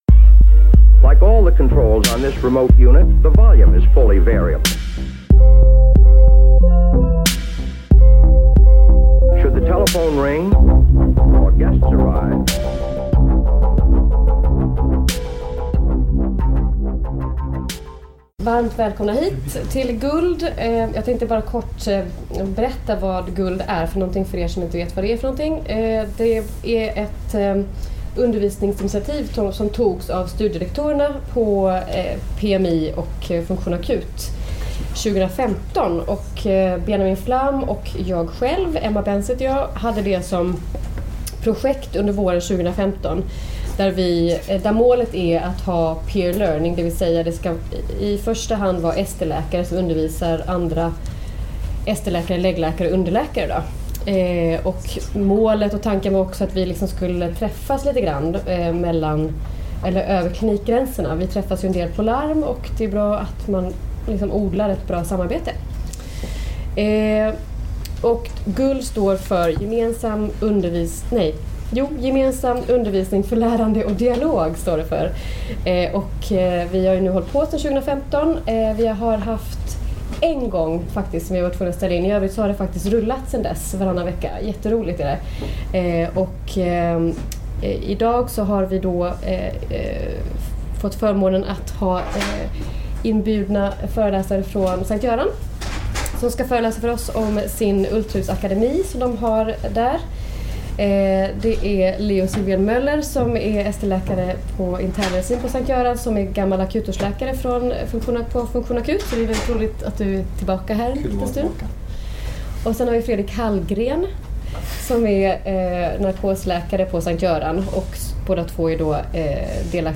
Inspelningen gjordes på GULD måndagen den 20 november 2017 på Karolinska sjukhuset i Solna.